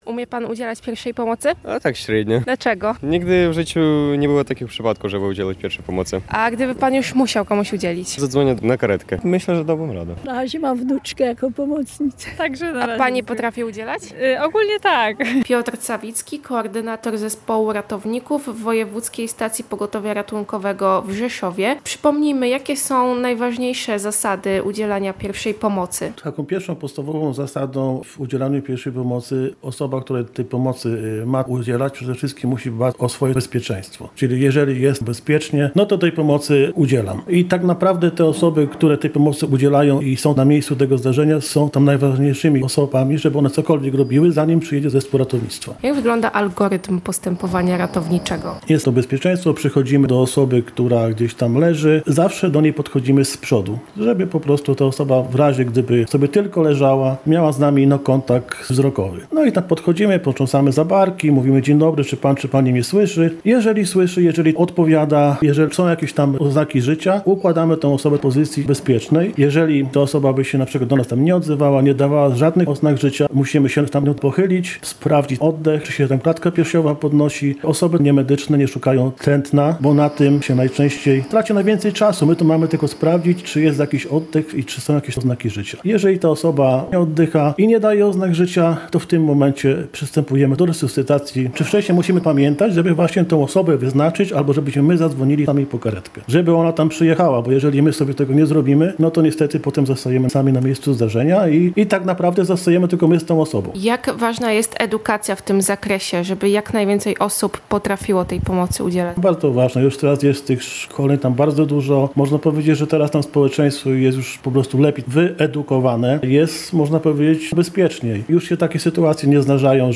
Sprawdziliśmy, co o tym wiedzą mieszkańcy, i poprosiliśmy ratownika medycznego o najważniejsze wskazówki.